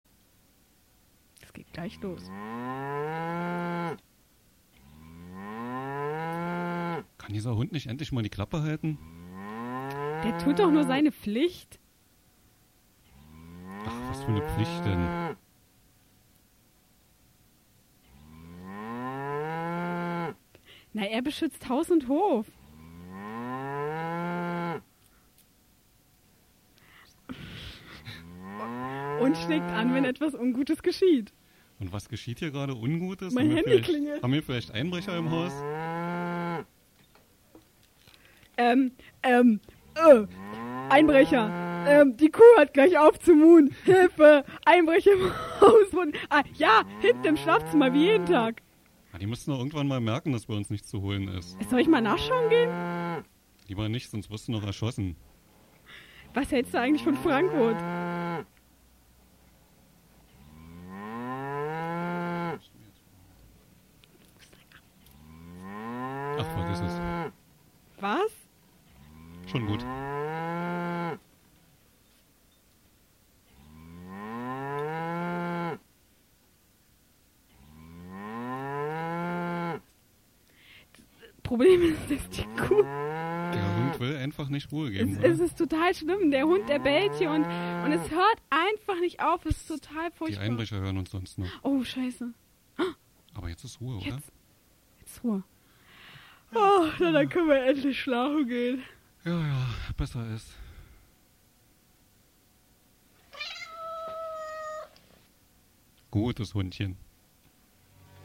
Zum Herunterladen: Unser erstes Live-Hörspiel!
hoerspiel.mp3